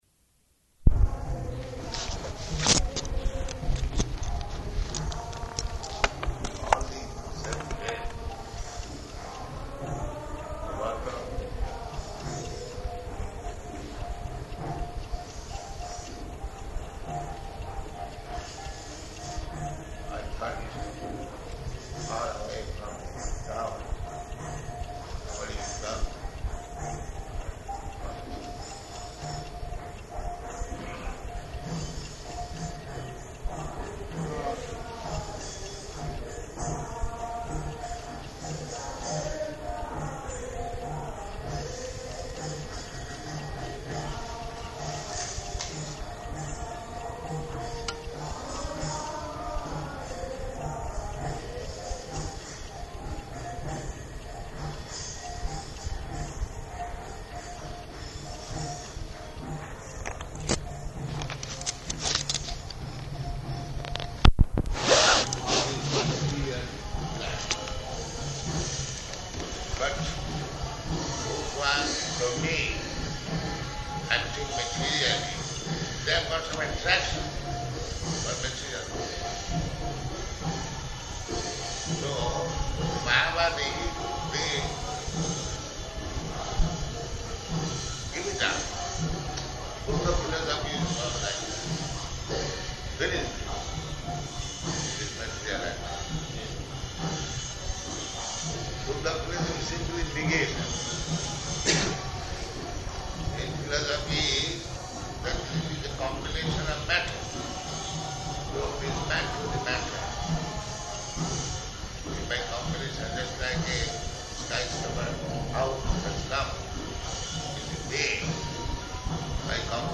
Room Conversation about Modern Soceity --:-- --:-- Type: Conversation Dated: June 16th 1975 Location: Honolulu Audio file: 750616R4.HON.mp3 [ kīrtana in background throughout] Prabhupāda: ...all these [indistinct], the workers.